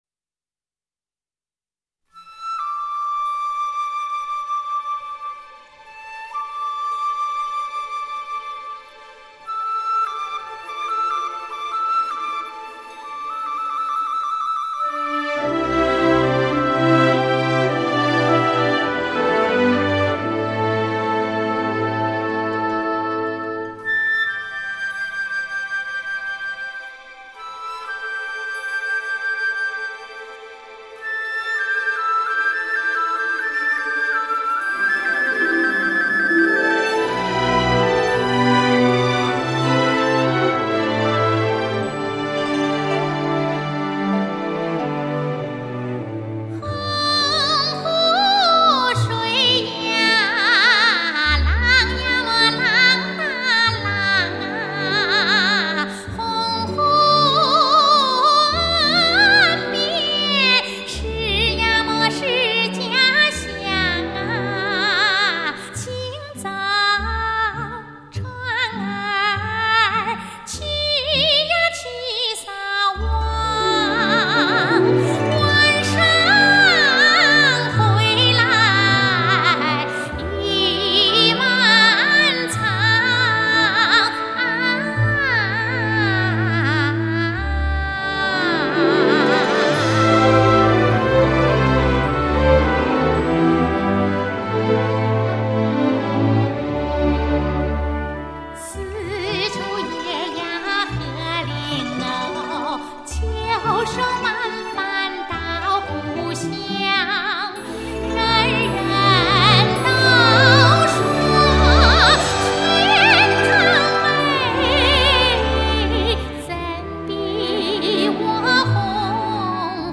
这就是任举作词、龙飞作曲的《太湖美》，这首歌曲吸取了苏南民歌和戏曲音乐的旋律特征、亲切流畅、秀丽抒情，具有浓郁的江南水乡特色。
《洪湖水，浪打浪》《太湖美》等水乡歌曲。